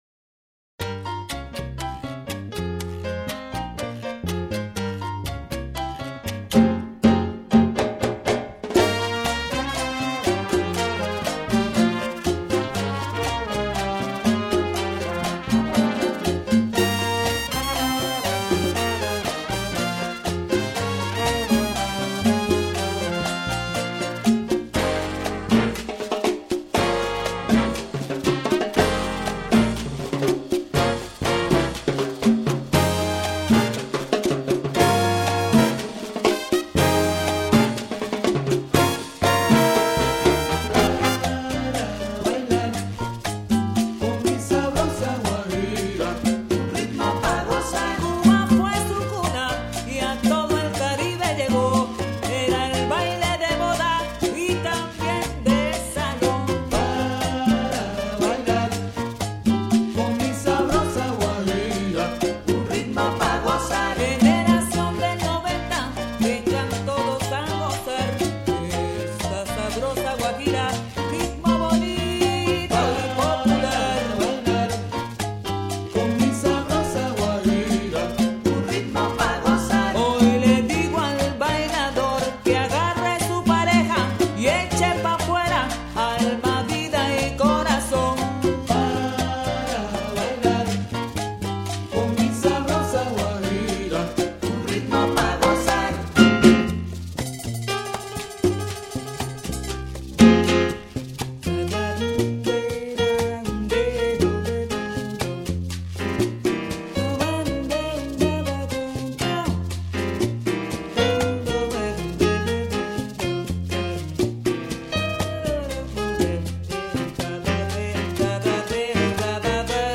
Wir bieten Ihnen hier eine kleine Auswahl an frei verfügbarer kubanischer Musik.
guajira_sabrosa.mp3